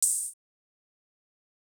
Hi-Hat Souffle.wav